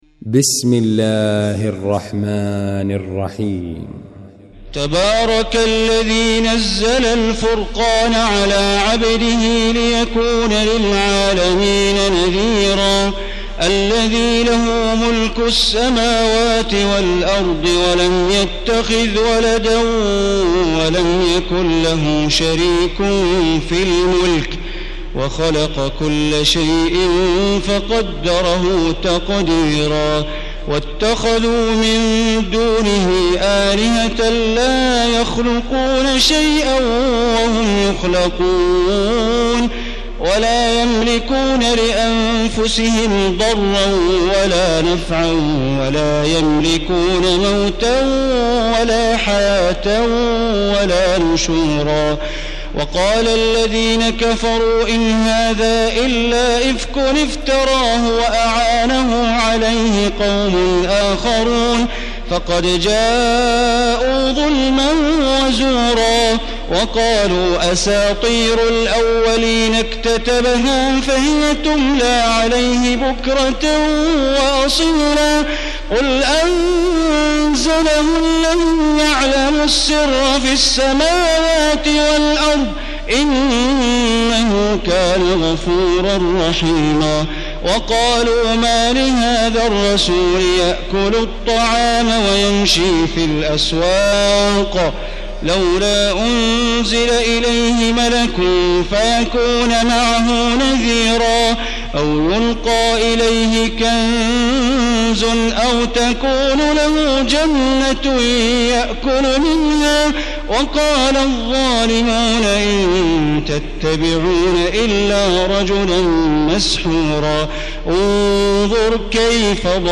المكان: المسجد الحرام الشيخ: معالي الشيخ أ.د. بندر بليلة معالي الشيخ أ.د. بندر بليلة سعود الشريم الفرقان